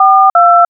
DTMF Audio for "42"
Audio Format: RIFF (little-endian) data, WAVE audio, Microsoft PCM, 16 bit, mono 44100 Hz